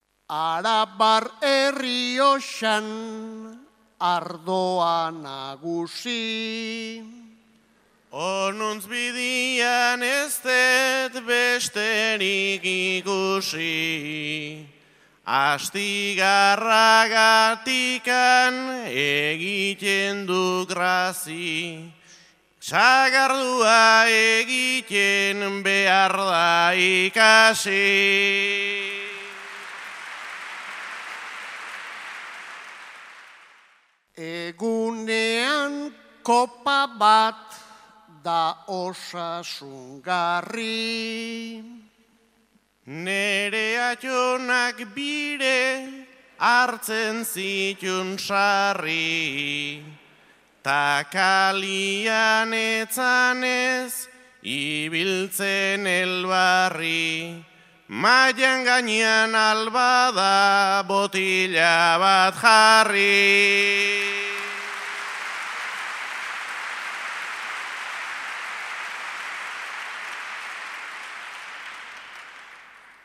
Bastidan Betsolari Txapelketako Nagusiko finalaurrekoa.